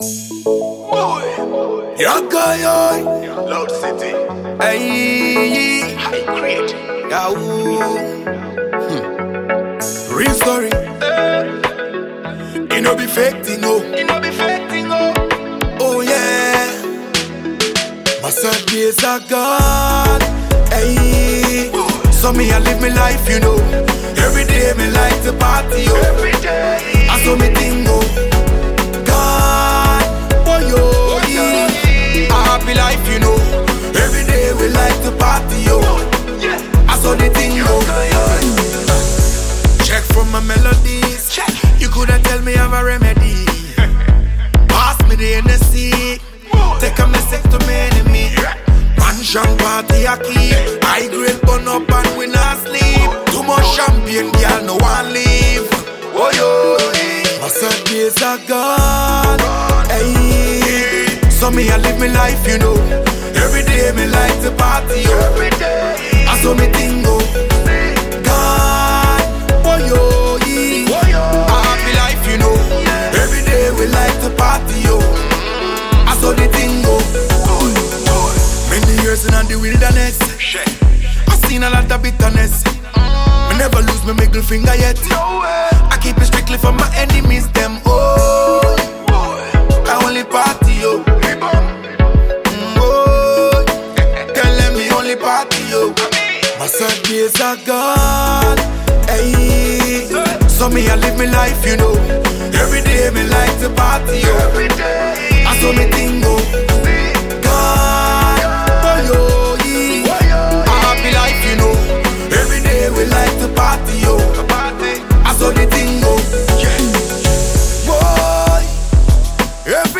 Ghanaian multiple award-winning dancehall musician
catchy single